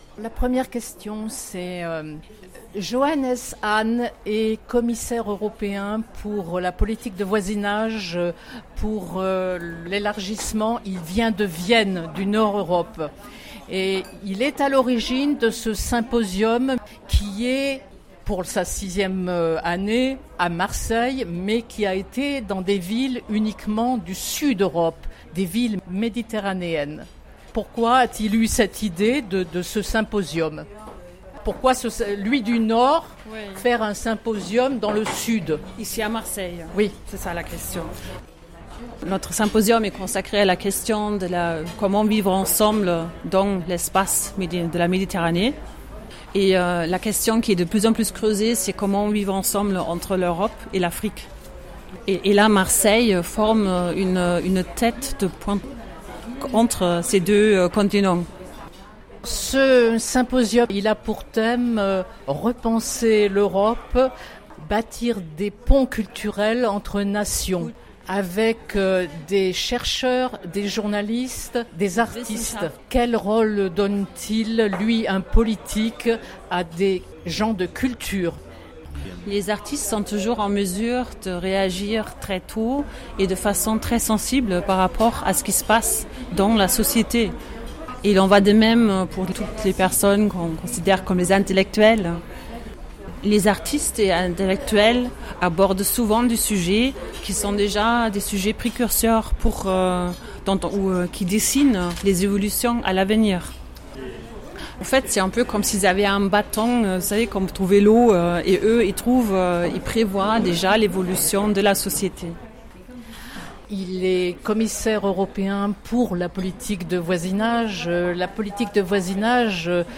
ste-011_itw_du_commissaire_hahn_08_10_17_avec_interprete.mp3